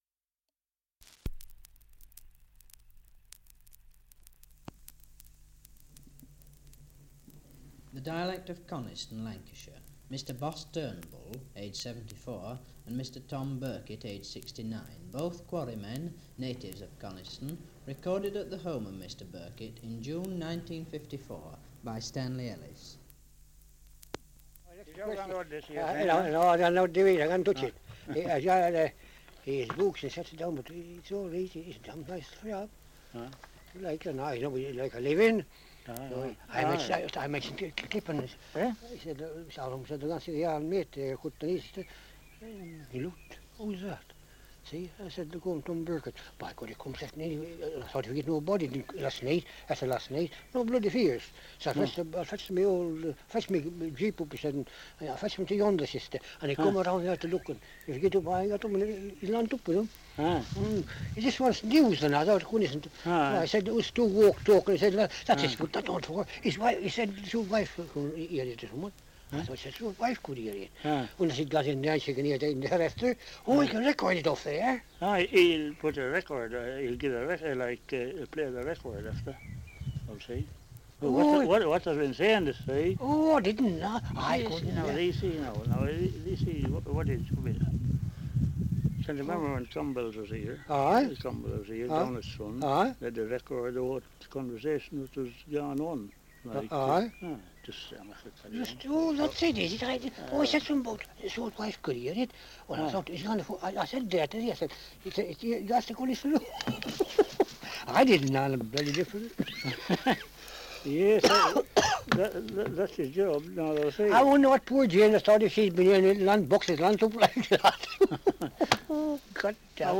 1 - Survey of English Dialects recording in Coniston, Lancashire
78 r.p.m., cellulose nitrate on aluminium